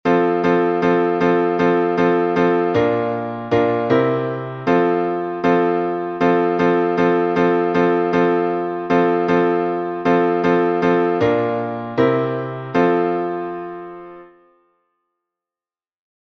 Обиходный напев №2